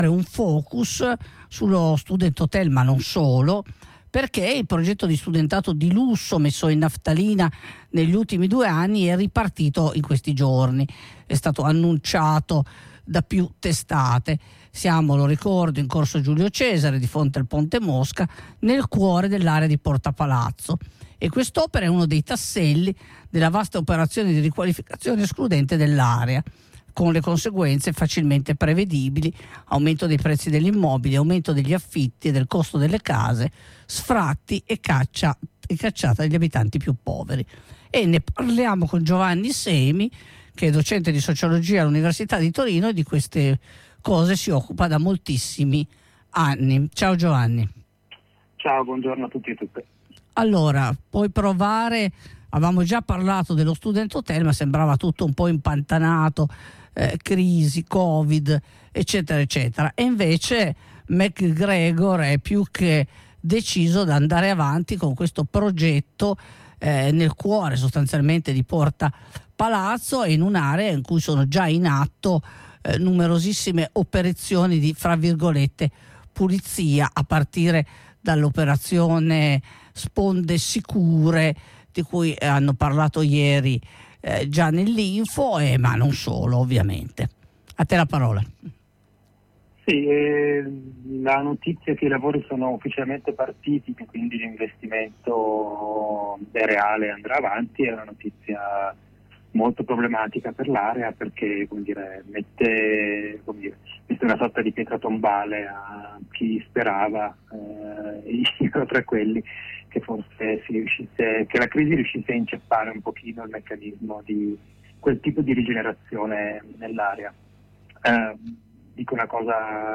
docente di sociologia all’università di Torino Ascolta la diretta: